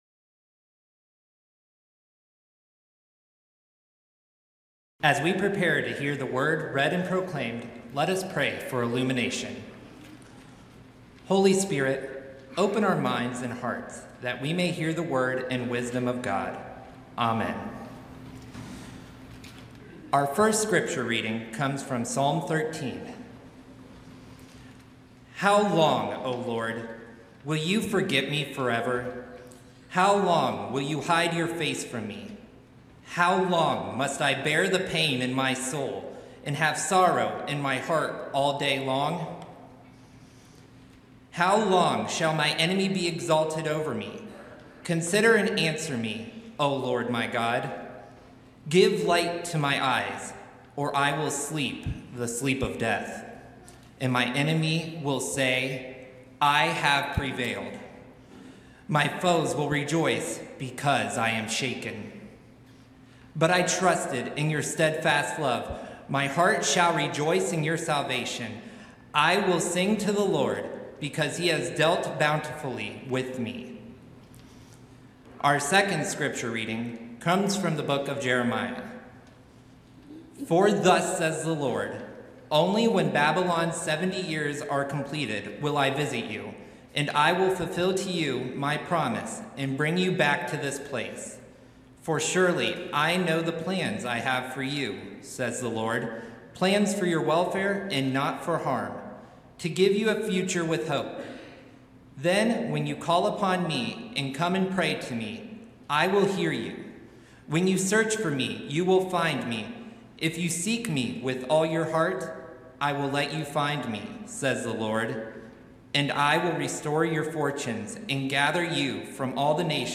Sermon-Feb-1-2026-God-is-Love-Prophetic-Imagination.mp3